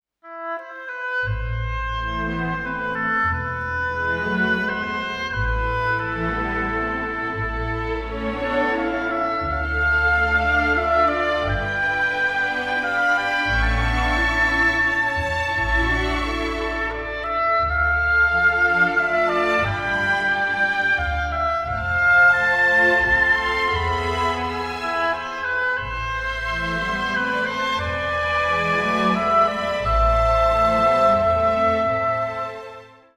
a sultry and suspenseful score